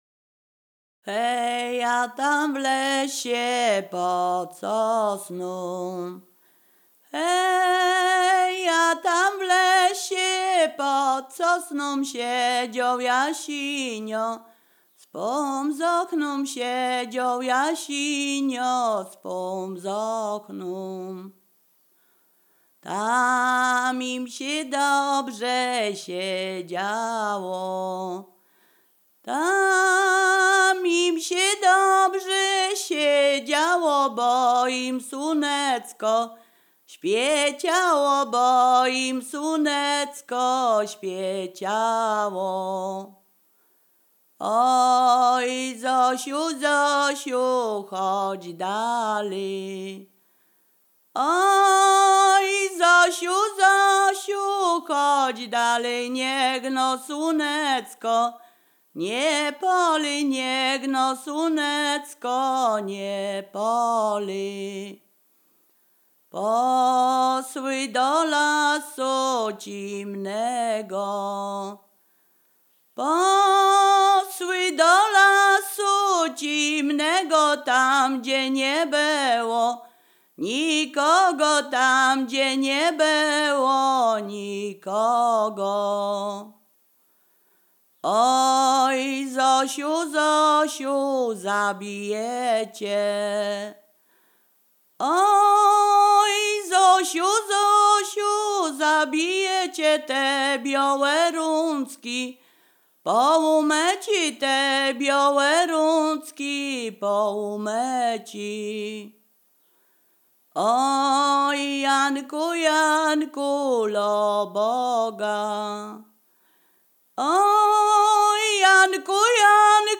Ziemia Radomska
województwo mazowieckie, powiat przysuski, gmina Rusinów, wieś Gałki
Ballada
ballady dziadowskie miłosne